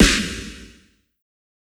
SNARE_HEART.wav